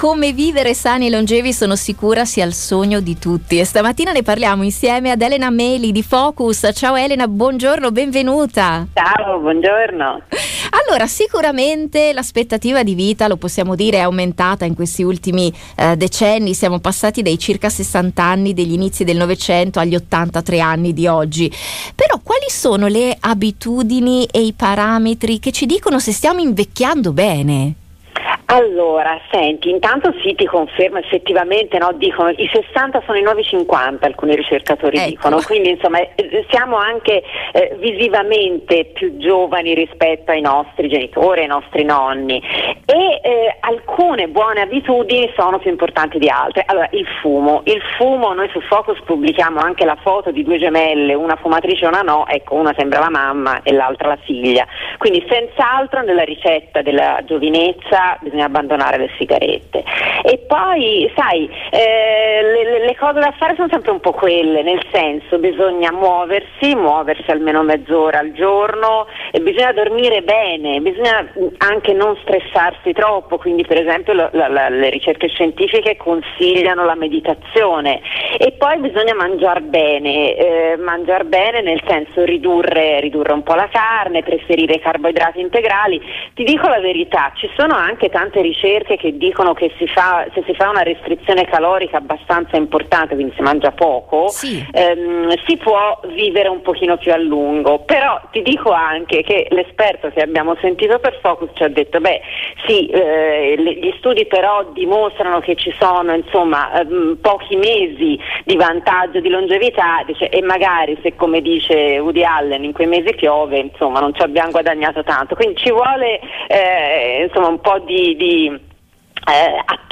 Ascoltando l’intervista scoprirete quali abitudini e parametri ci dicono se invecchiamo bene; come si misura l’età biologica; se esiste una ricetta della giovinezza; cosa ci mantiene più giovani e in forma e il ruolo chiave dei rapporti sociali: